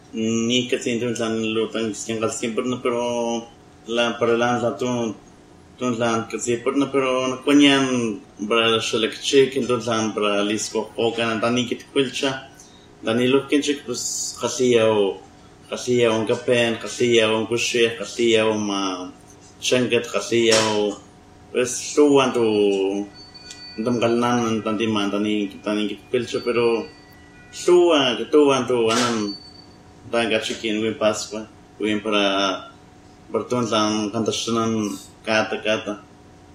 I think I hear many examples of the lateral fricative [ɬ] and a couple lateral affricates [tl], even maybe a word than ends in [tlan], like many placenames in Mexico and Guatemala? However, I don’t hear any aspirated consonants, ejective consonants, high central vowels like [ɨ], lexical tone, creaky voice, or breathy voice.
The recording comes from Wikitongues .